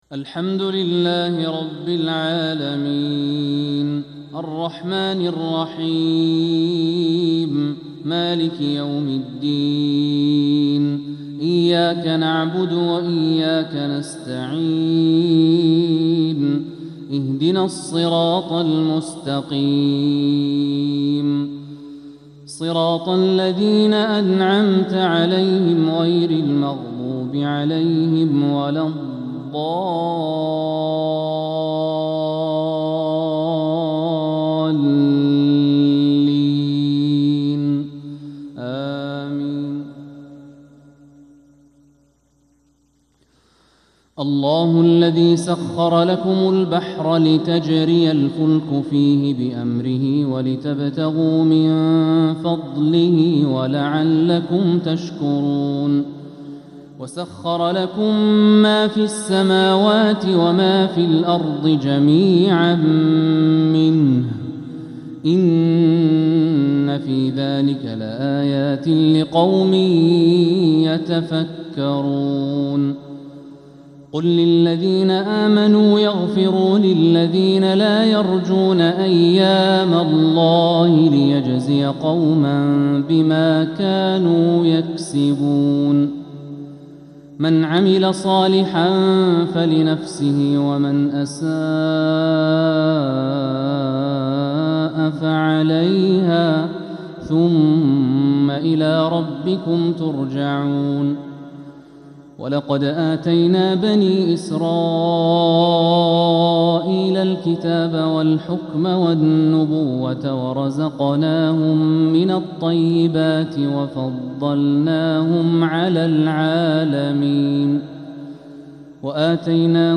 فجر الأربعاء 14 محرم 1447هـ من سورة الجاثية 12-27 | Fajr prayer from Surat Al-Jathiyah 9-7-2025 > 1447 🕋 > الفروض - تلاوات الحرمين